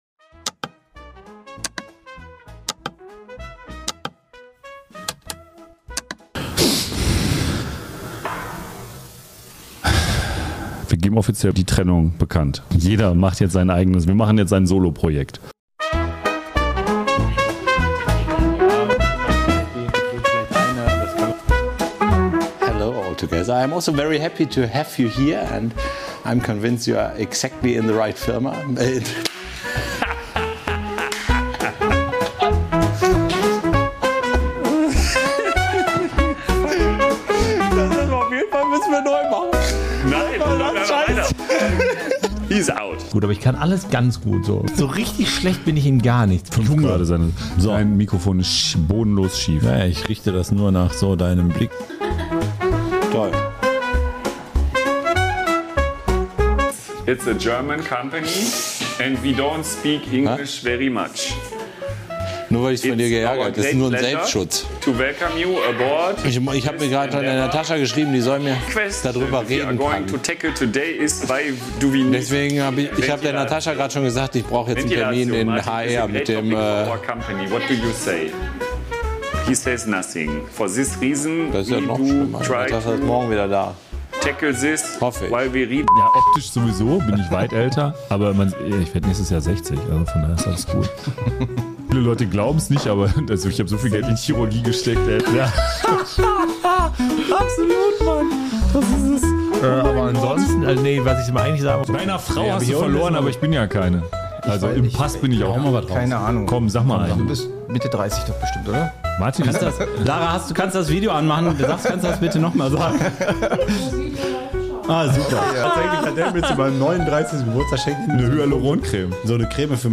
Besonderes: unsere Outtakes, also genau die Momente, die wir sonst